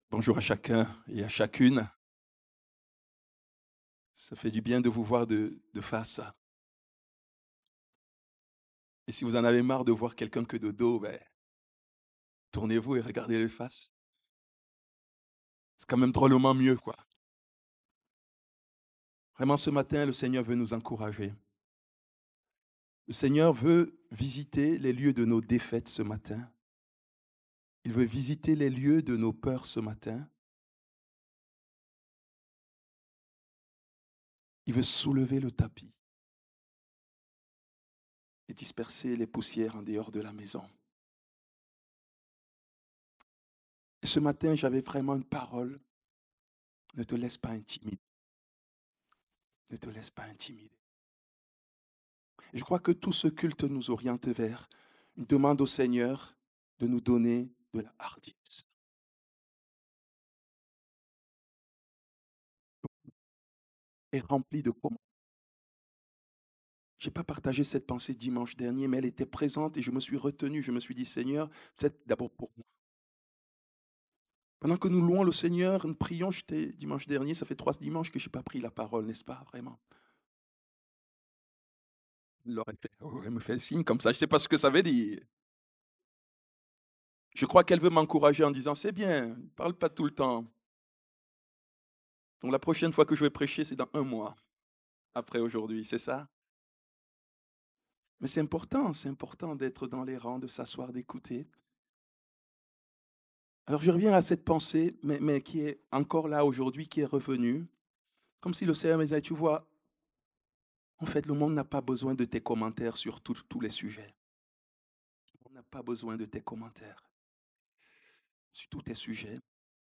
Dimanche de l’Eglise persécutée
prédication